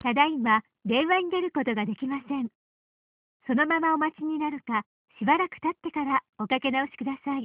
OnHoldMessage1.amr